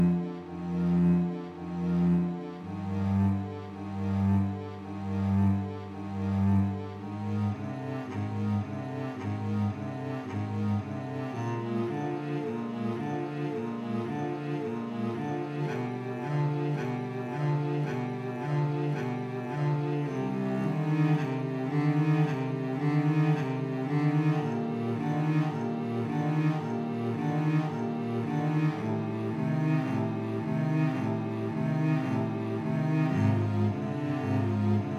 Жанр: Музыка из фильмов / Саундтреки
# Soundtrack